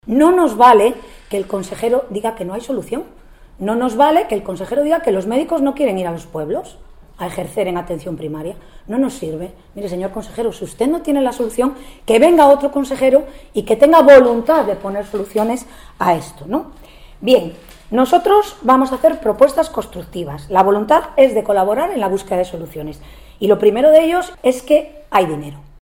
Ver declaraciones de Paula Fernández Viaña, diputada del Partido Regionalista de Cantabria y portavoz en materia de Sanidad; y de Eduardo Ortiz, vicepresidente de la Federación de Municipios de Cantabria.